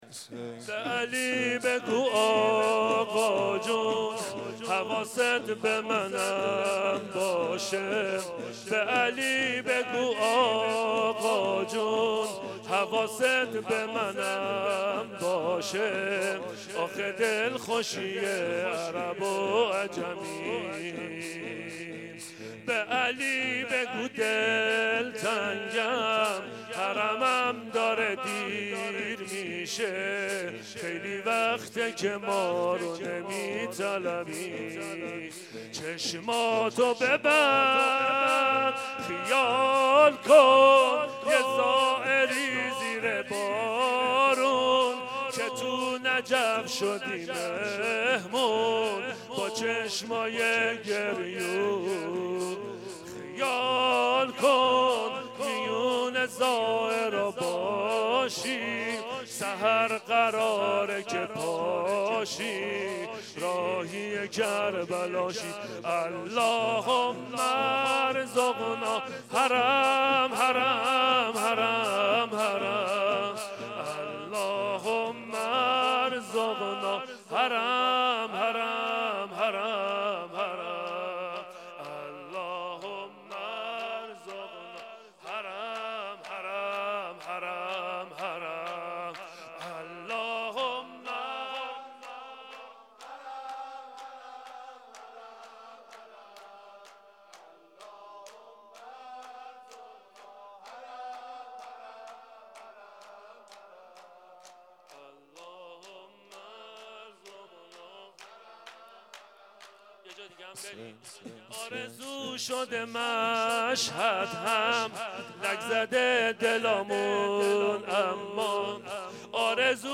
شب هجدهم ماه رمضان/ ۳۰ فروردین ۴۰۱ حضرت علی علیه السلام مداحی شور ماه رمضان اشتراک برای ارسال نظر وارد شوید و یا ثبت نام کنید .